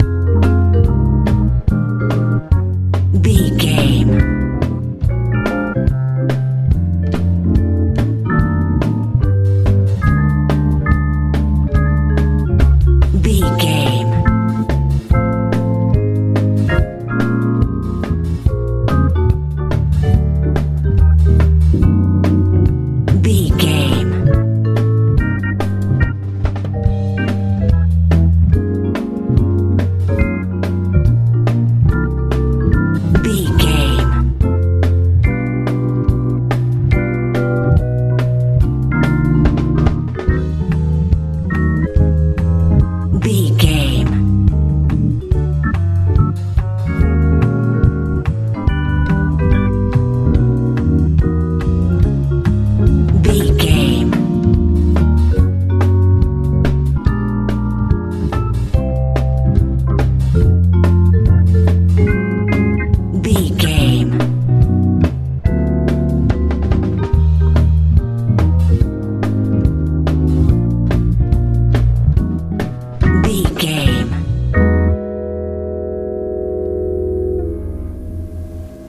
jazz pop
Aeolian/Minor
bouncy
organ
bass guitar
drums
tranquil
soothing
smooth
soft
elegant